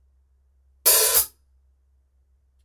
Zildjian 14.25" K Custom Hybrid Hi-Hat Cymbals
The 14 1/4 Hi-Hats capture the same sound as the existing 13 1/4 models but with extra volume and more breadth of sound Zildjian K Custom.
Ride Chick Stick Chick